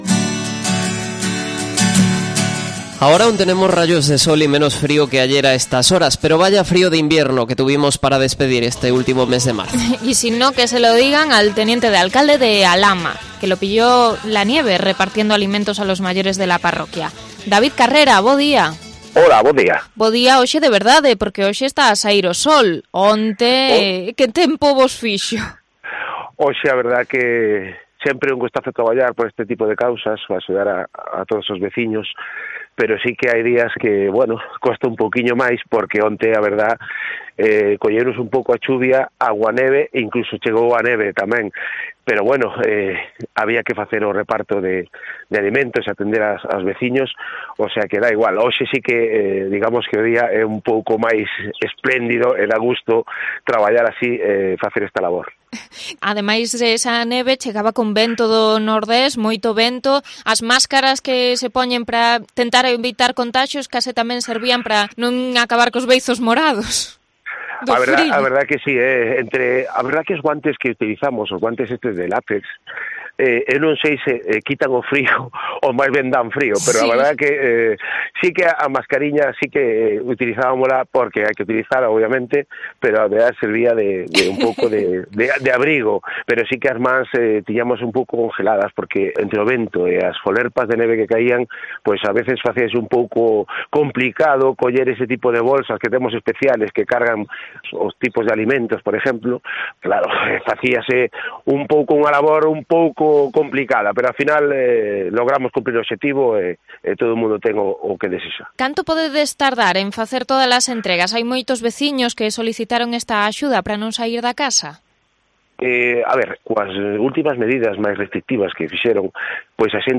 Entrevista al teniente de alcalde de A Lama, en un impás en su ruta de reparto